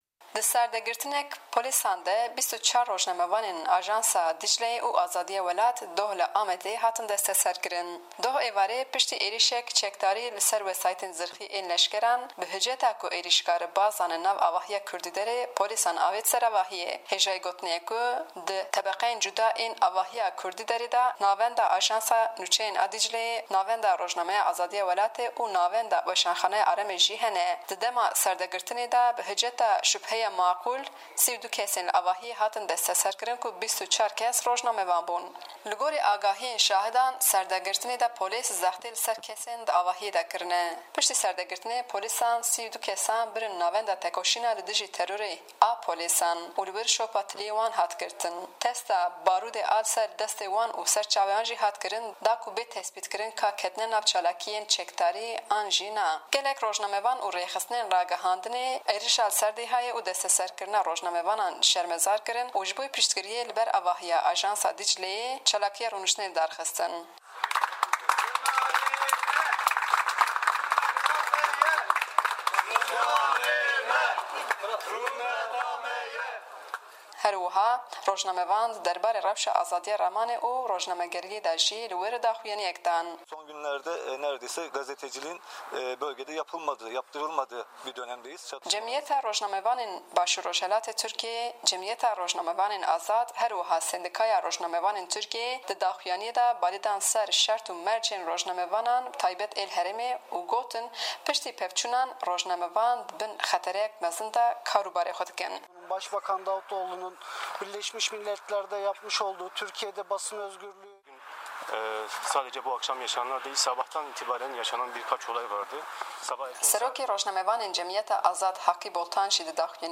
Raportek li ser Berdana Rojnamevanên Kurd